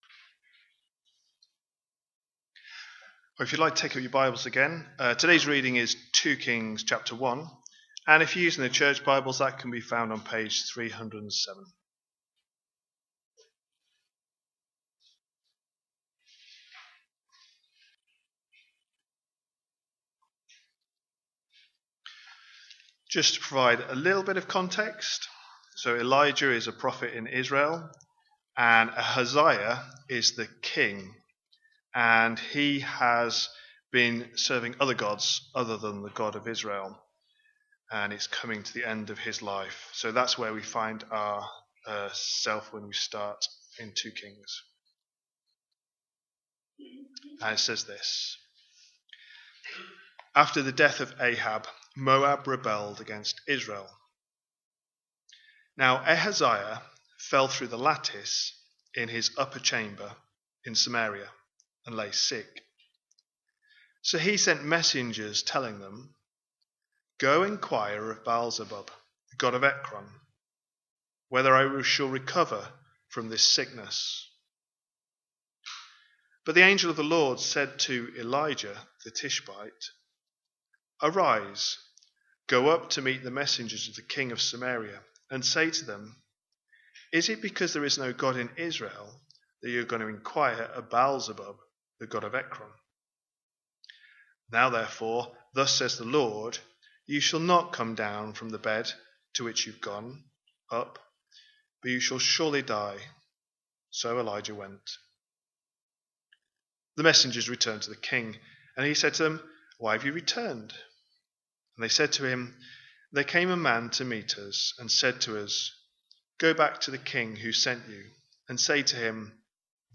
A sermon preached on 28th December, 2025, as part of our New Year series.